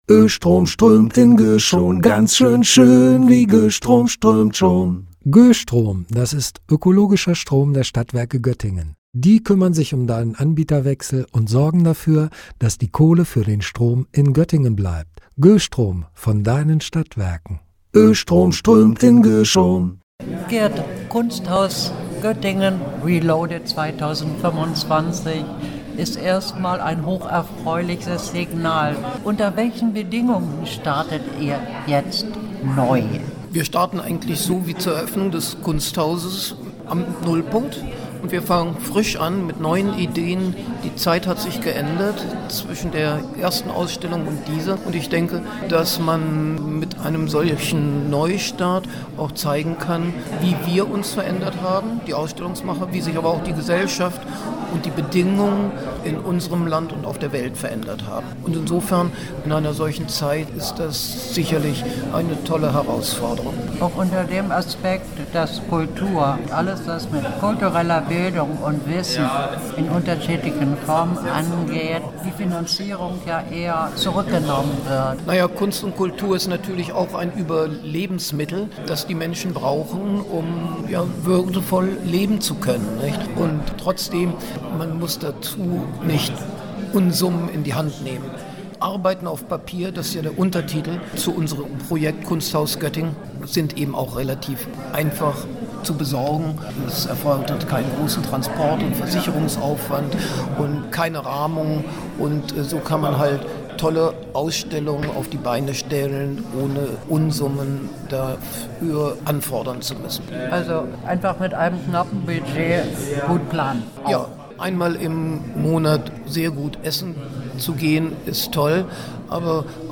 Beiträge > Neustart für das Kunsthaus – Gespräch mit Verleger und Initiator Gerhard Steidl - StadtRadio Göttingen